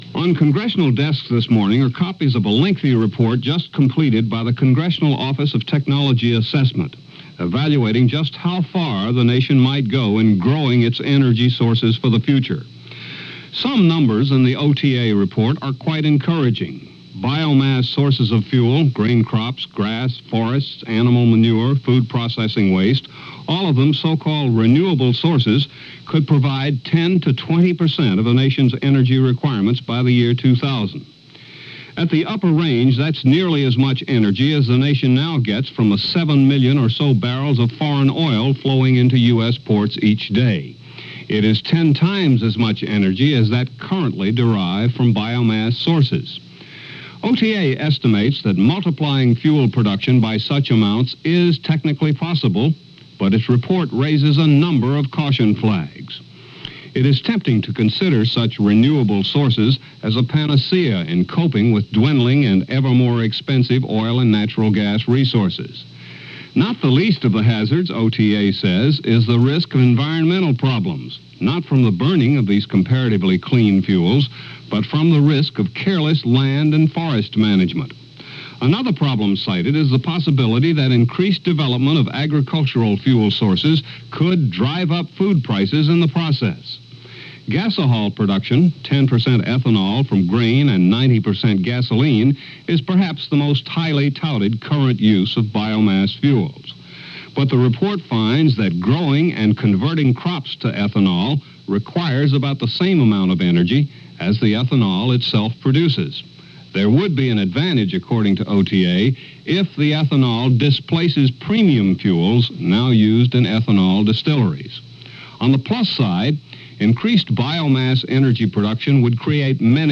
– July 28, 1980 – Frontline Report – CBS World News Roundup – CBS Radio News On The Hour – Gordon Skene Sound Collection –